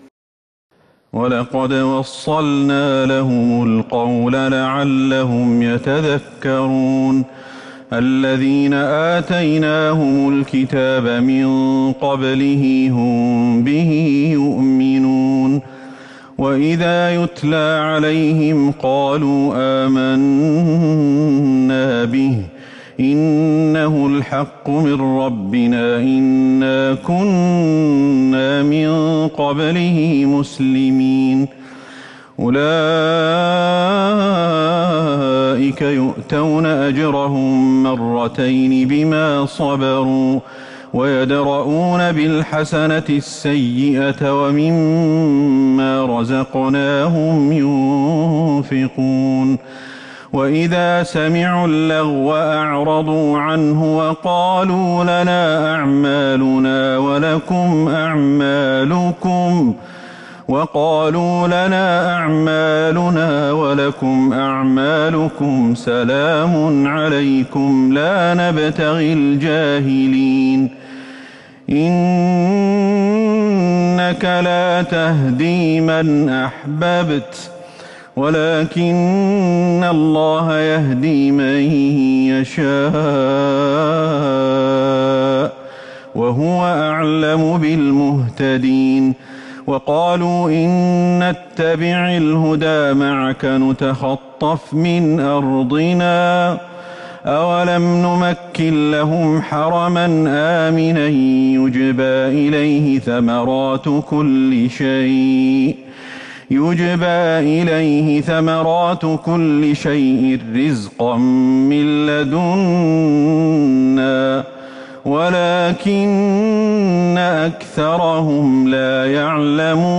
تهجد ٢٣ رمضان ١٤٤١هـ من سورة القصص { ٥١-٨٨ } والعنكبوت { ١-٢٧ } > تراويح الحرم النبوي عام 1441 🕌 > التراويح - تلاوات الحرمين